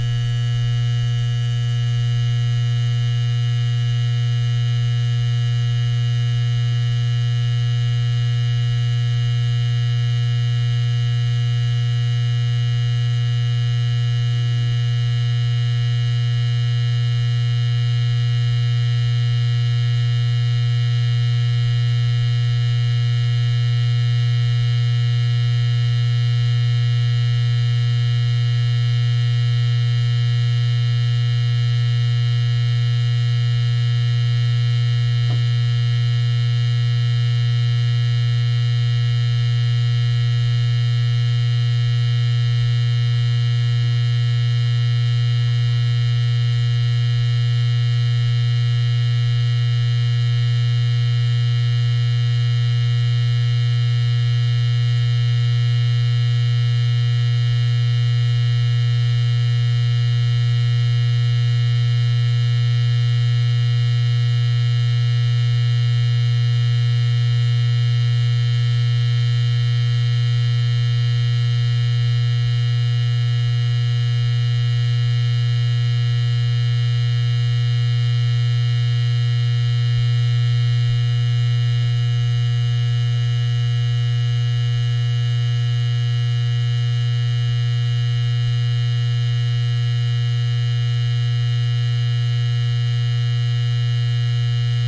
随机 " 霓虹灯立体声蜂鸣器盒 嗡嗡嗡立体声接近低切的味道
描述：霓虹灯立体声蜂鸣器盒嗡嗡声嗡嗡声立体声关闭lowcut to taste.flac
Tag: 关闭 嗡嗡声 低胸 霓虹灯 嗡嗡声 音响 蜂鸣器